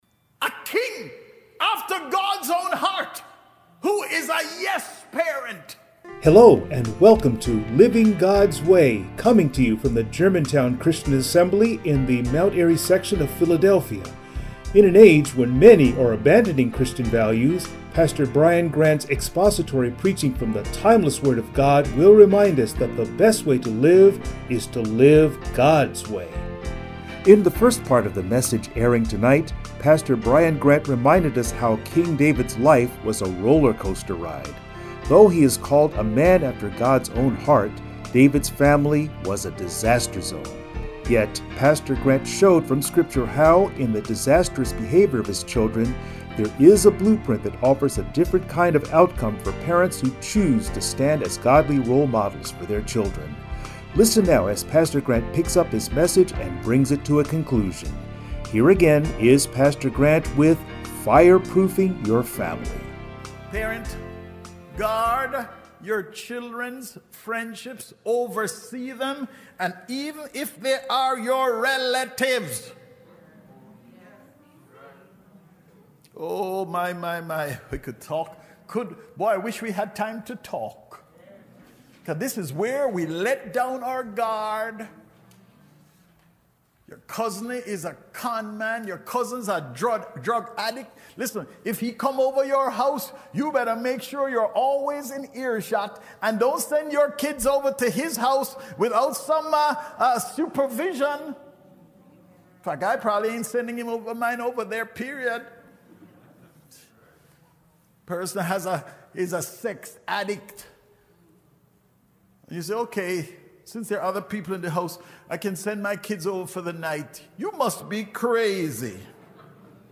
Passage: 2 Samuel 13:1-19 Service Type: Sunday Morning